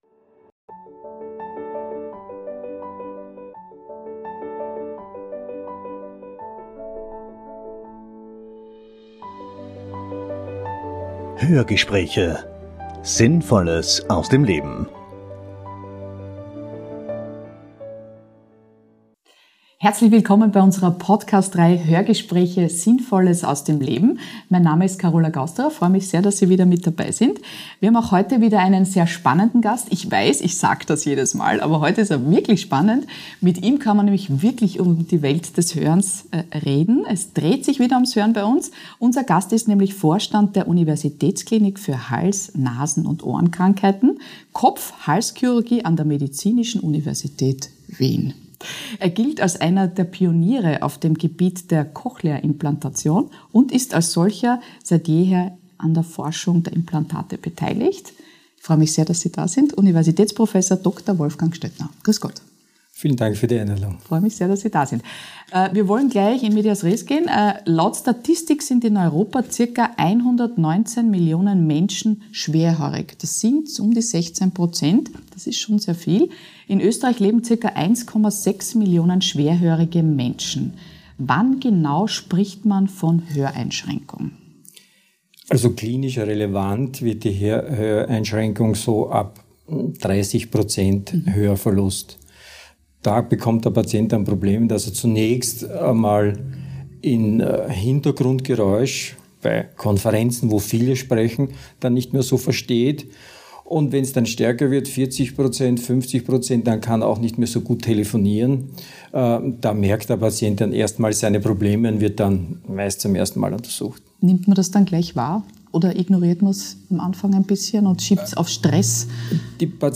Einer der Pioniere bei Hörimplantationen im Gespräch ~ Hörgespräche Podcast